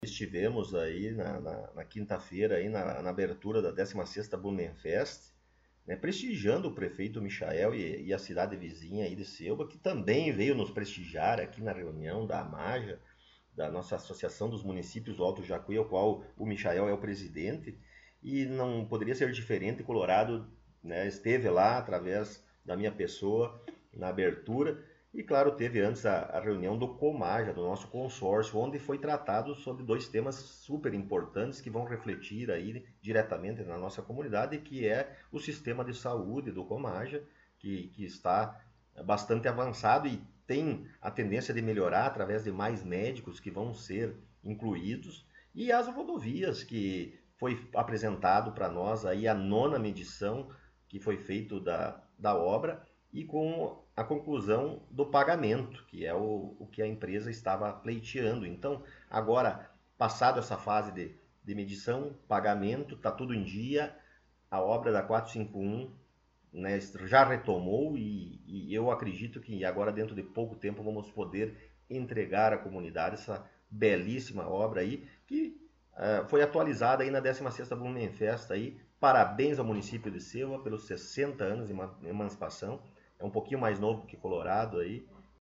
Após algumas semanas de espera, nossa equipe teve a oportunidade de entrevistar o prefeito Rodrigo Sartori em seu gabinete na Prefeitura Municipal.